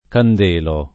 candela [kand%la] s. f. — ant. candelo [